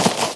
default_grass_footstep.1.ogg